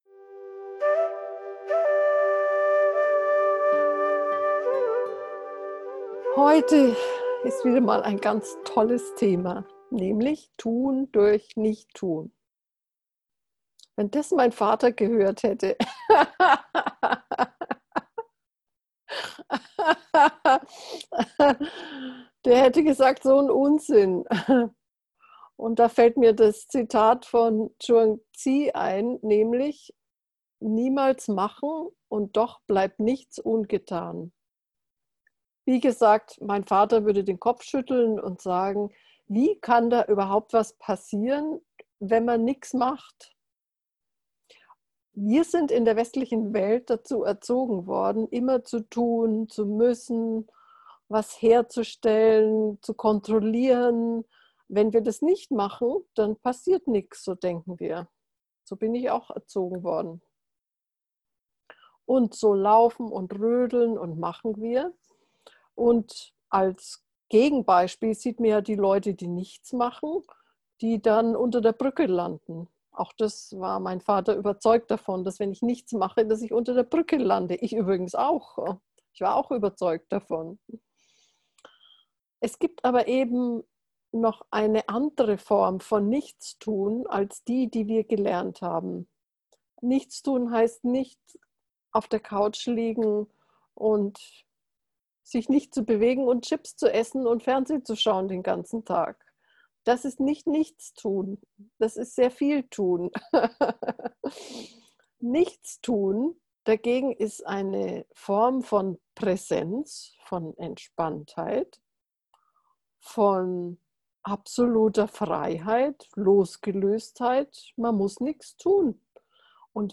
Eine geführte Meditation.